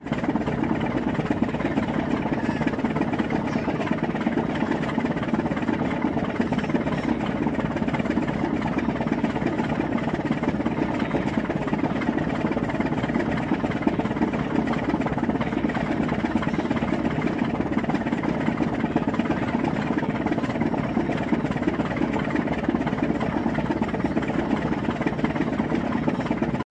希腊 " 机动船 1
描述：柴油引擎小船在港口。 由我自己用ZOOM H4录制
标签： 船舶 汽车 港口 柴油发动机
声道立体声